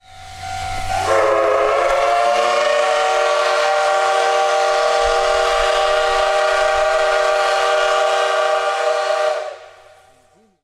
C58363whistle.mp3